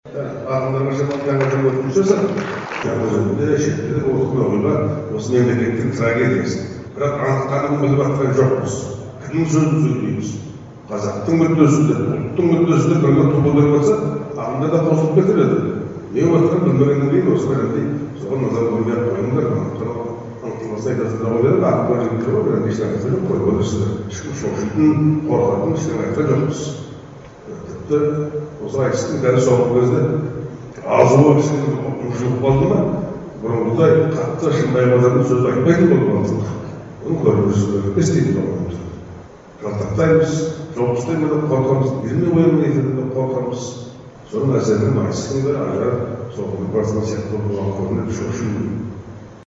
Жазушы Софы Сматаевтың Жаңаөзен туралы сөзі